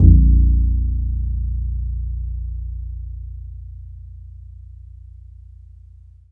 DBL BASS CN1.wav